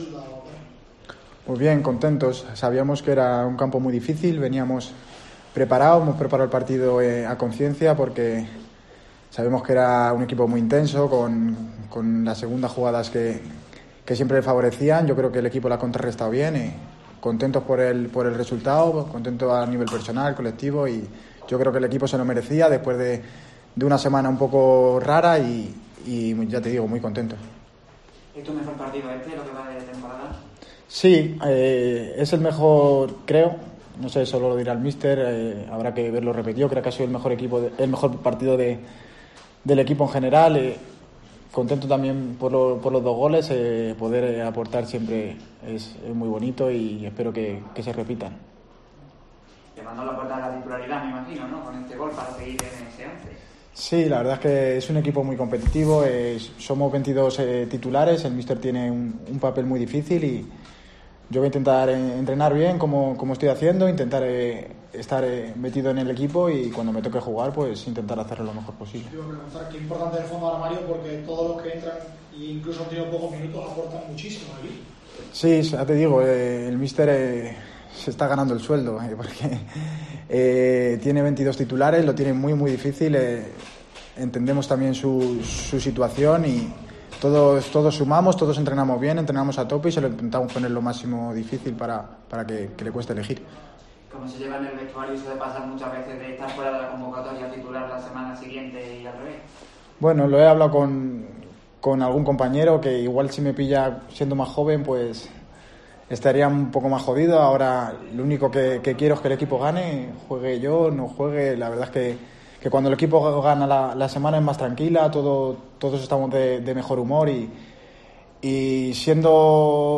Rueda de Prensa Don Benito